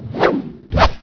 slash.wav